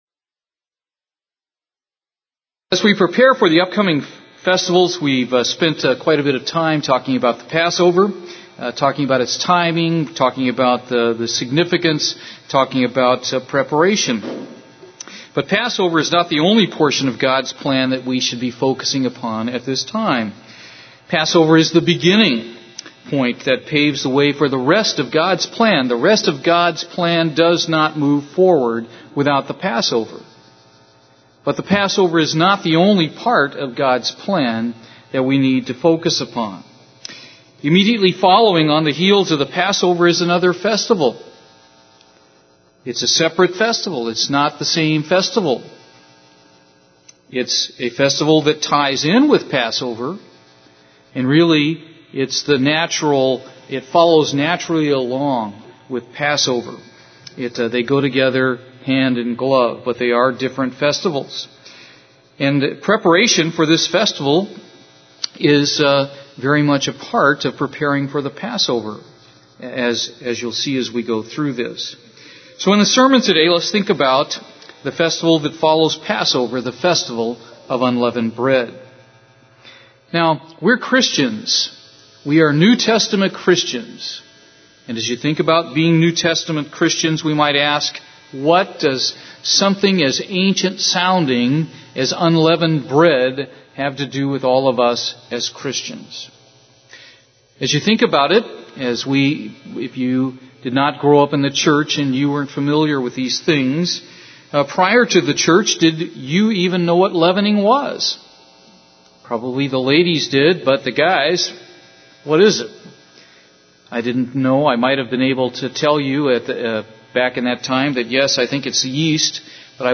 Sermons
Given in Houston, TX